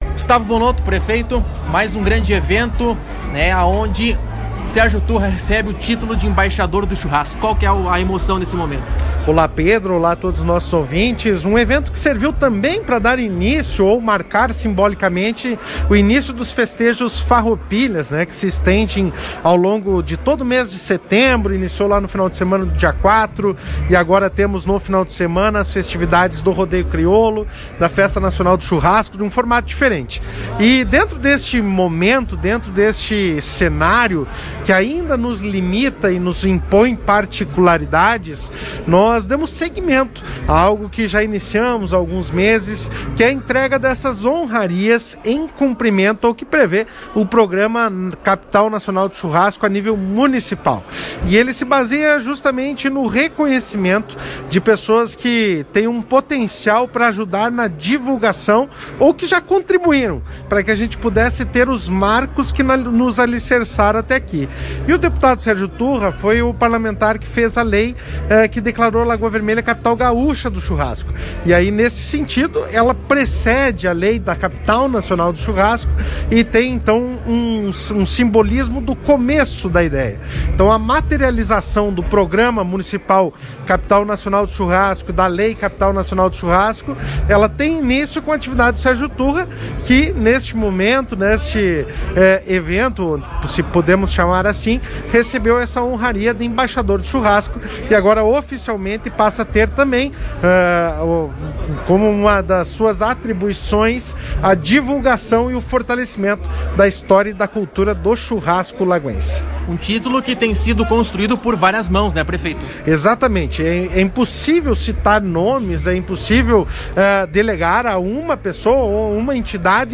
O deputado e o prefeito de Lagoa Vermelha, Gustavo Bonotto (PP), concederam entrevista à Tua Rádio Cacique.